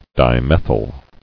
[di·meth·yl]